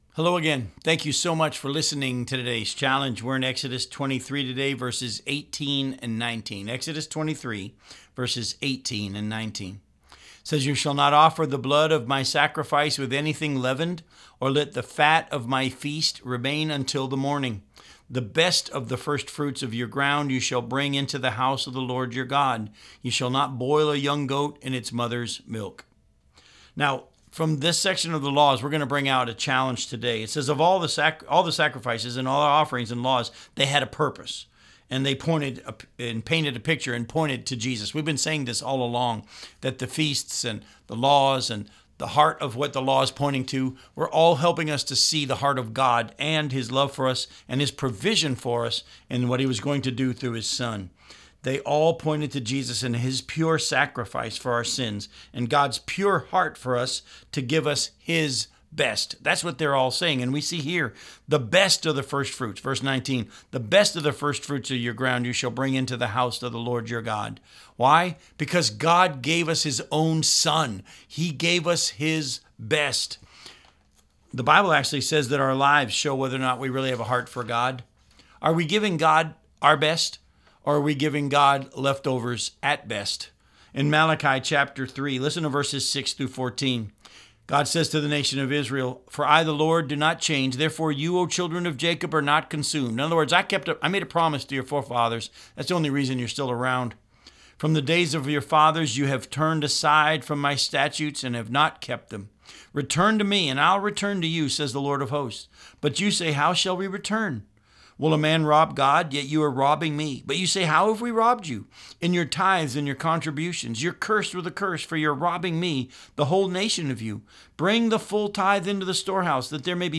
radio program aired on WCIF 106.3 FM in Melbourne, Florida